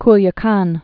(klyə-kän)